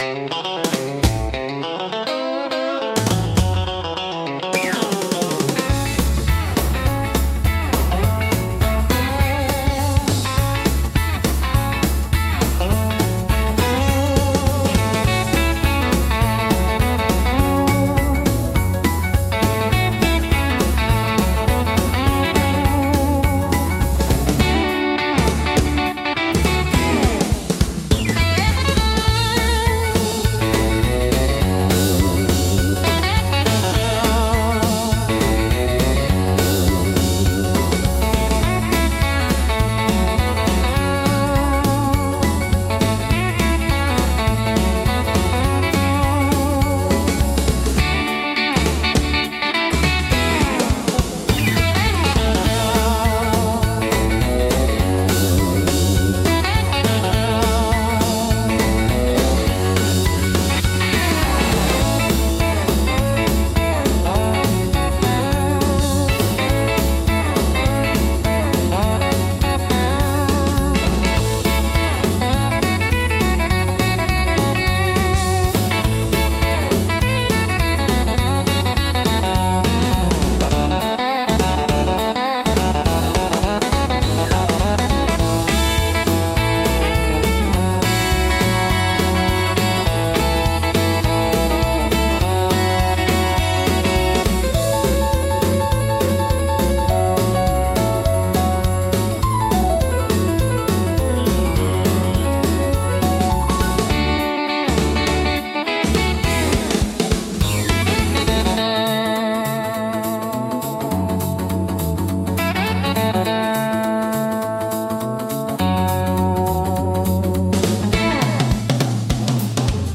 懐かしさと若々しいエネルギーが共存し、楽しい空気づくりに効果的なジャンルです。